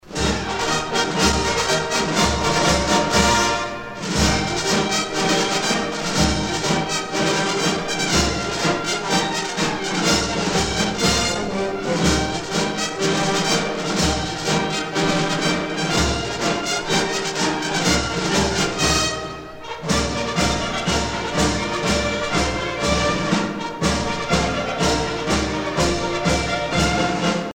à marcher
militaire